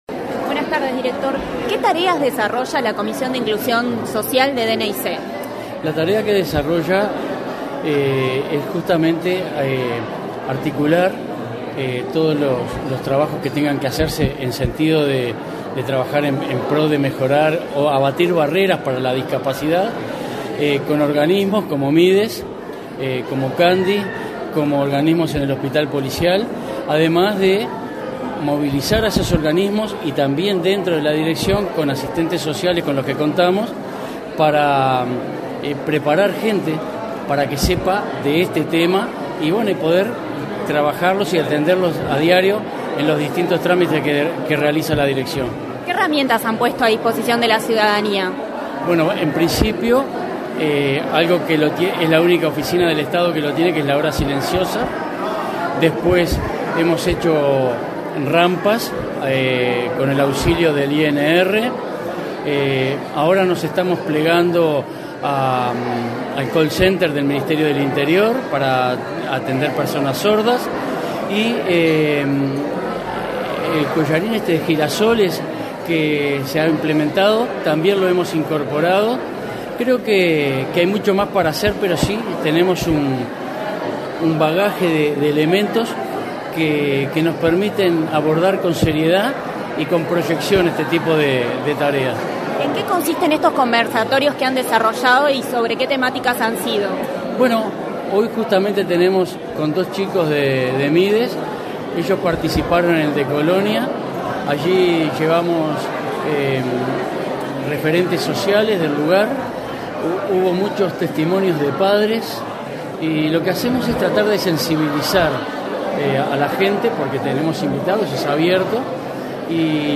Declaraciones del director nacional de Identificación Civil, José Luis Rondán
Tras el tercer encuentro Trabajando por un Estado Inclusivo, el director nacional de Identificación Civil, José Luis Rondán realizó declaraciones a la